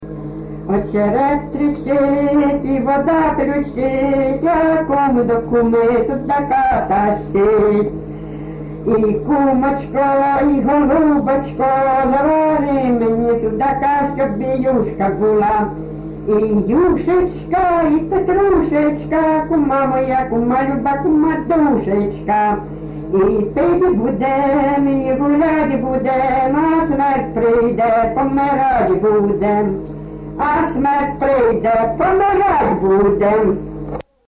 ЖанрЖартівливі
Місце записус. Остапівка, Миргородський район, Полтавська обл., Україна, Полтавщина